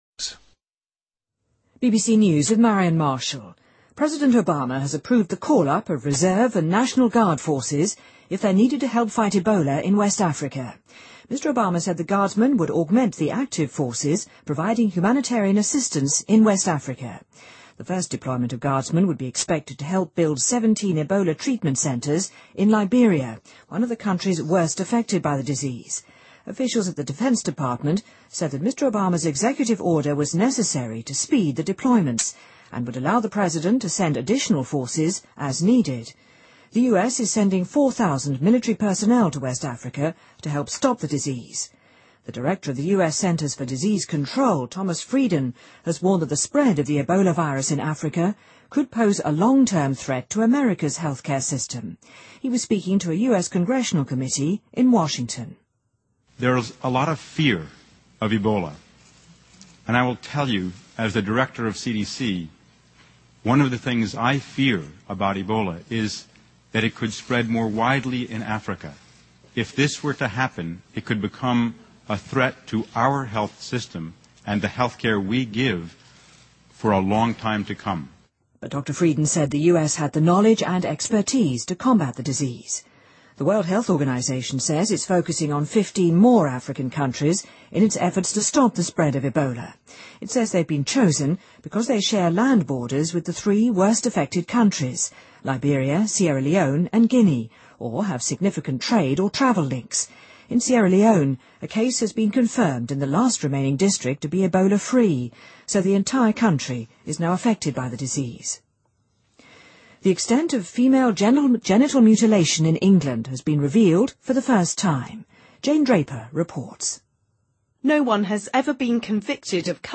BBC news:奥巴马总统已经批准召集国民警卫队为对抗西非埃博拉病毒做准备|BBC在线收听